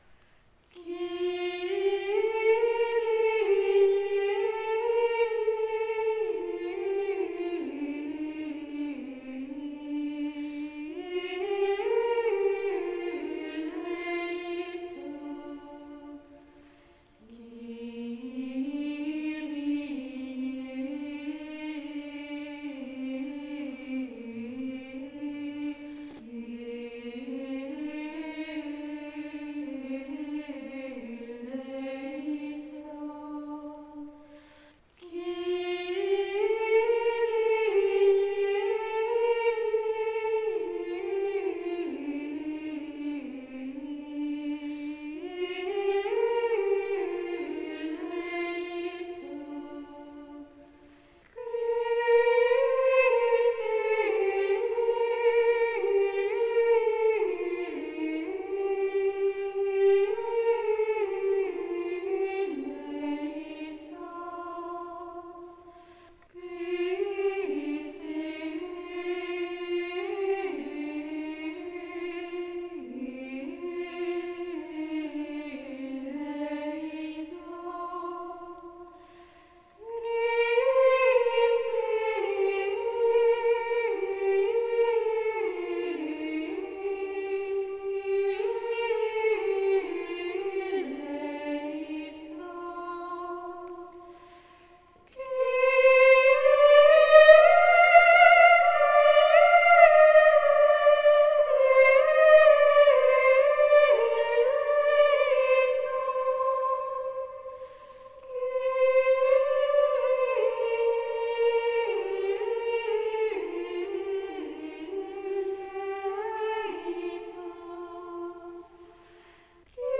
En effet, le compositeur a ajouté une deuxième mélodie d’eléison, uniquement située sur le premier des trois derniers Kyrie, alors que les huit autres eléison suivent une formule identique.
Kyrie-6-ad-libitum-choeur-Épisèmes.mp3